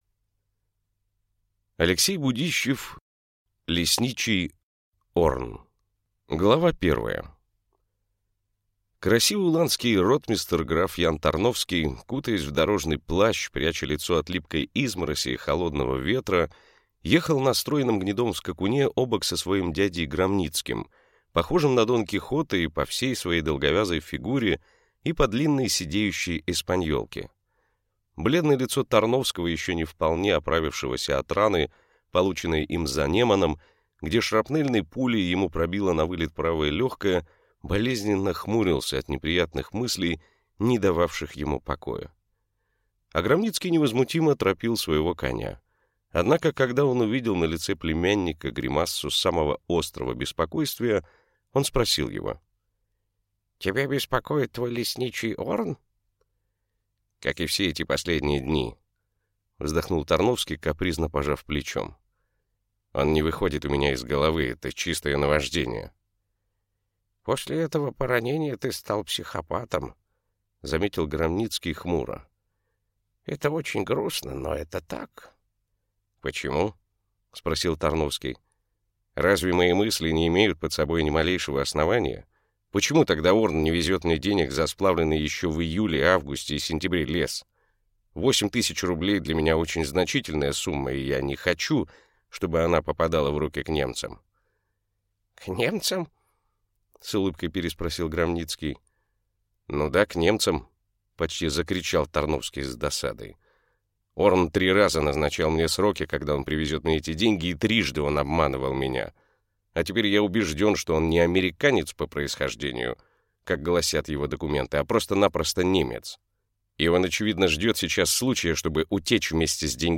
Аудиокнига Лесничий Орн | Библиотека аудиокниг